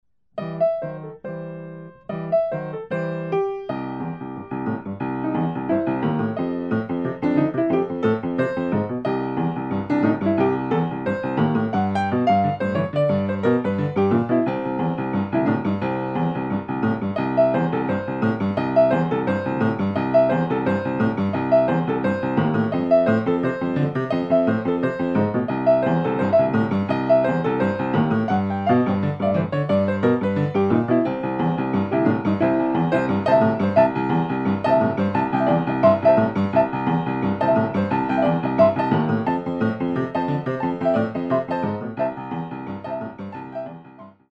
Style: Boogie Woogie Piano